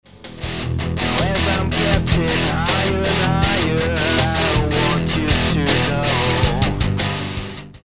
Mit dem habe ich damals Gesangsaufnahmen leicht angezerrt, kam echt super rüber.